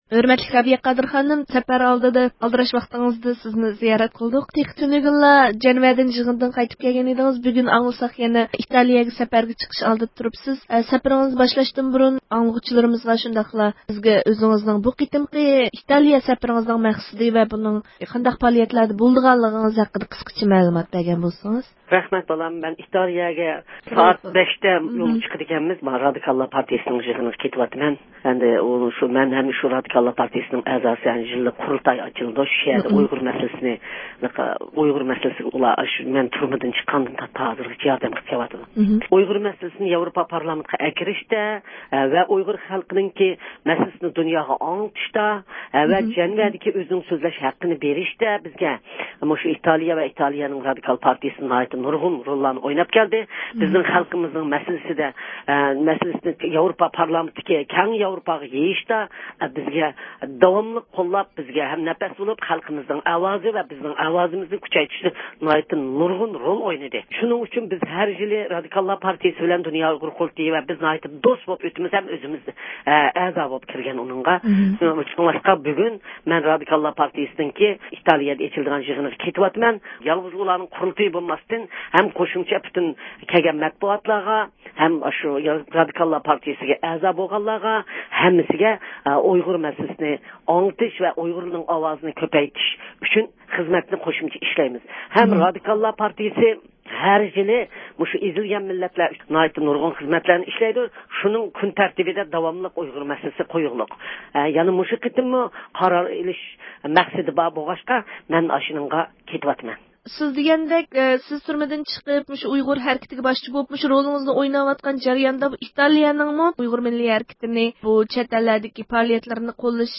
رابىيە قادىر خانىم سەپەرگە ئاتلىنىش ئالدىدا زىيارىتىمىزنى قوبۇل قىلىپ، بۇ قېتىمقى سەپىرىنىڭ مەقسەت ۋە نىشانلىرى ھەققىدە مەلۇمات بەردى.